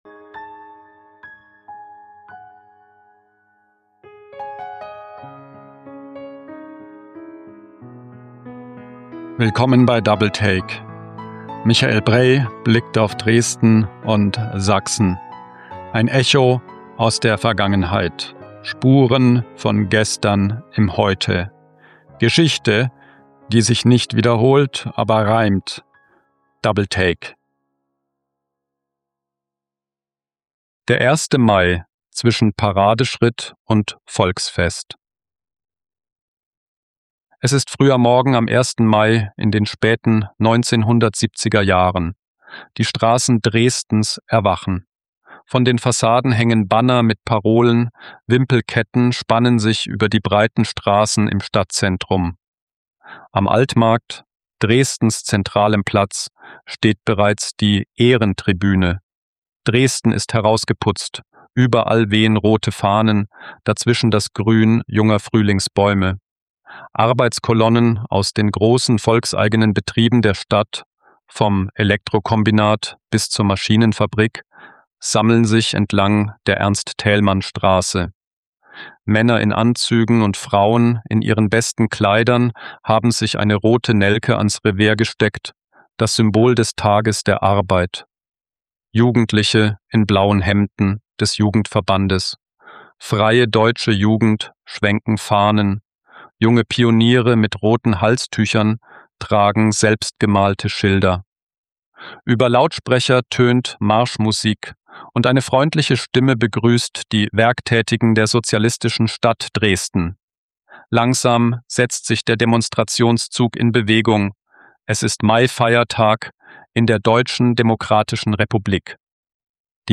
Dieser Essay taucht ein in den Dresdner Maifeiertag der späten DDR-Jahre: die akribische Parteiorganisation, den inszenierten Aufmarsch am Altmarkt, die allgegenwärtige Stasi im Hintergrund – und das Volksfest, das danach folgte und für viele der eigentlich schöne Teil des Tages war.